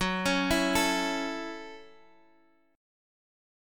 Listen to F#m7 strummed